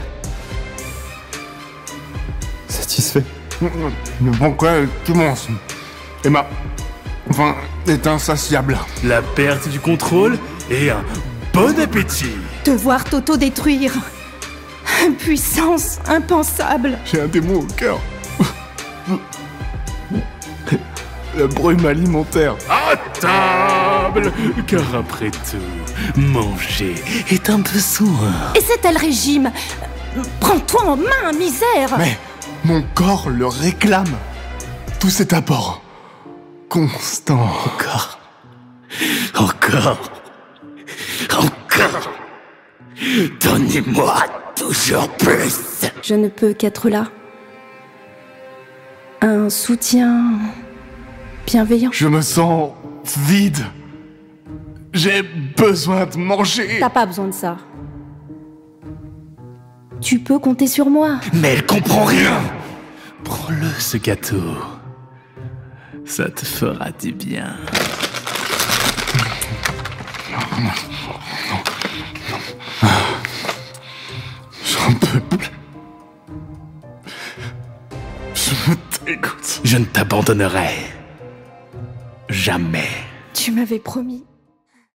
Démo livre audio "l'Hôtel des Démons"